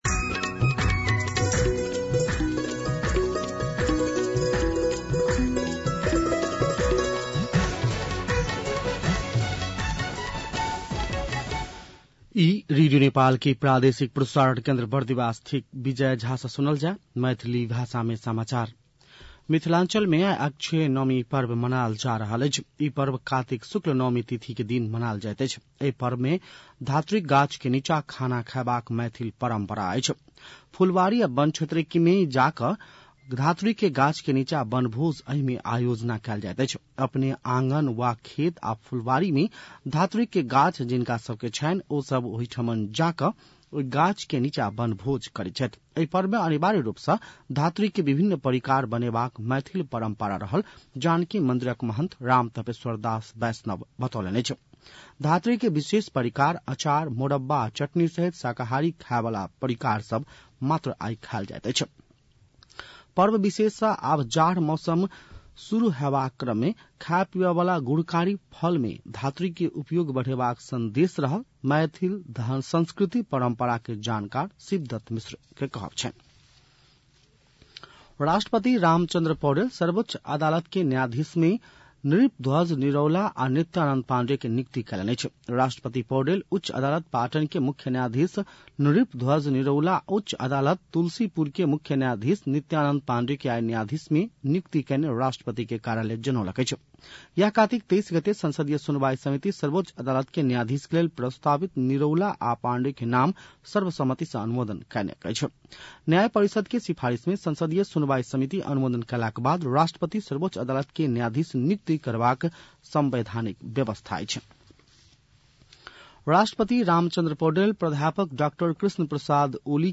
मैथिली भाषामा समाचार : २६ कार्तिक , २०८१